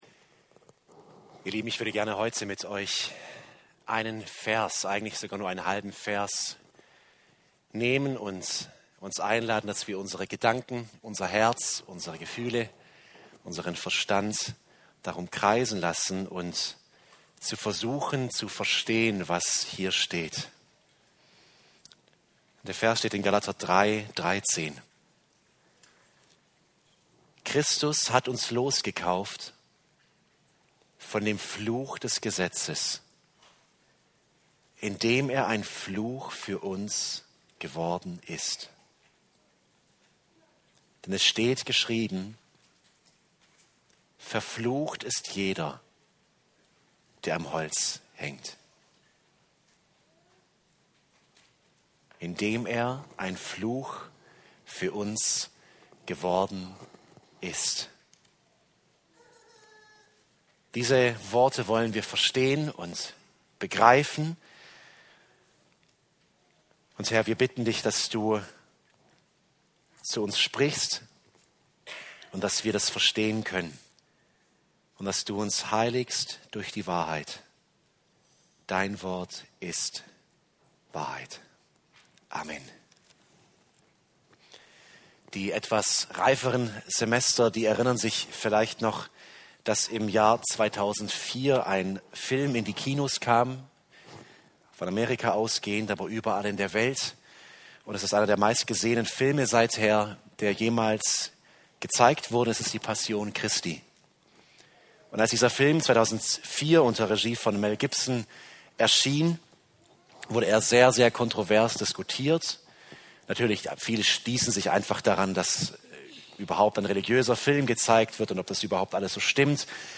Ein Fluch für uns geworden ~ Evangelische Freikirche Böbingen | Predigten Podcast